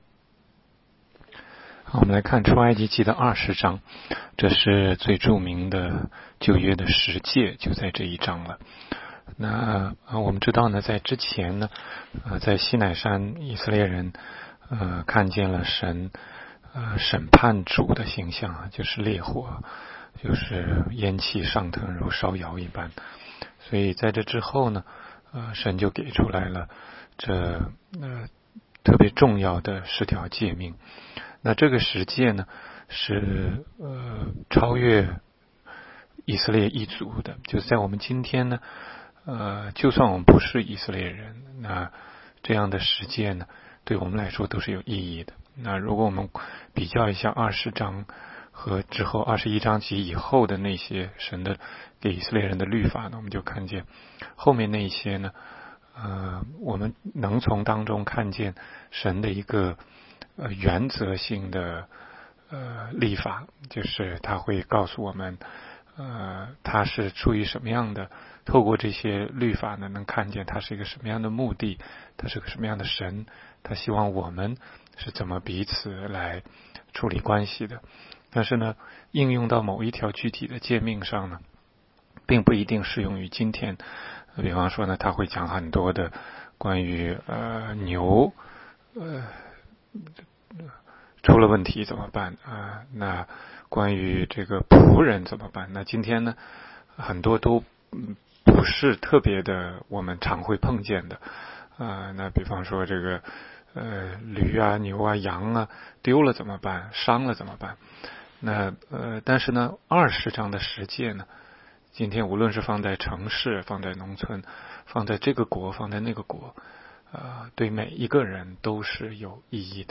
每日读经
每日读经-出20章.mp3